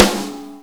normal-hitclap2.wav